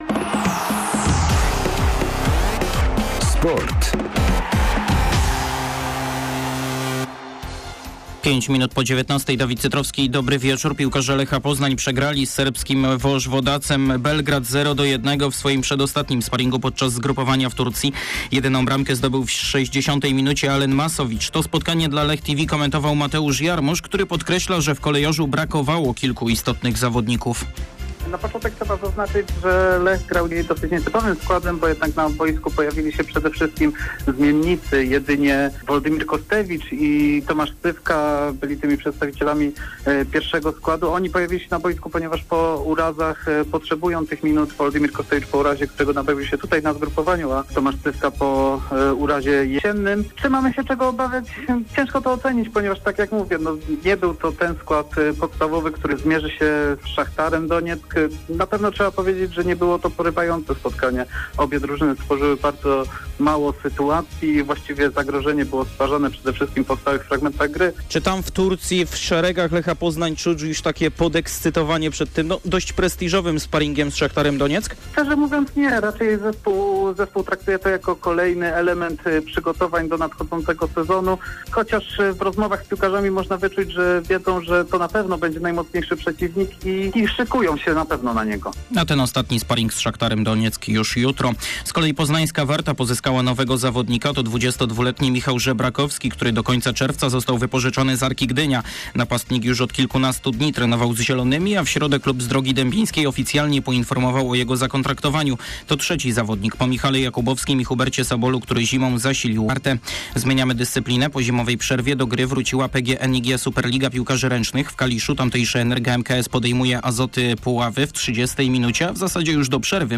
30.01. serwis sportowy godz. 19:05